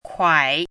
chinese-voice - 汉字语音库
kuai3.mp3